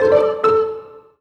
happy_collect_item_09.wav